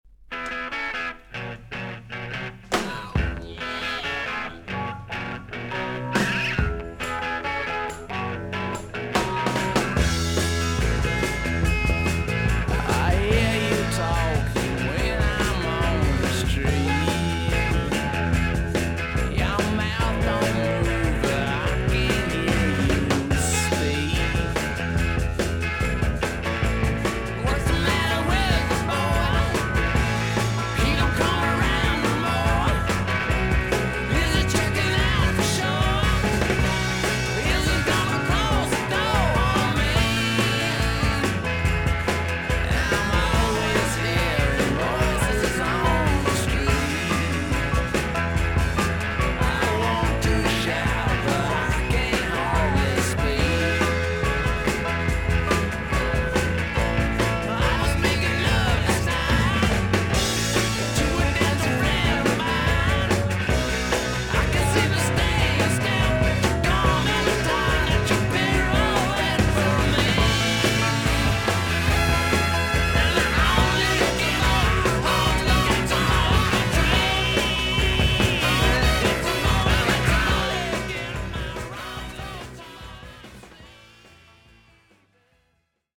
少々軽いパチノイズの箇所あり。クリアな音です。盤面も音もきれいなコンディションです。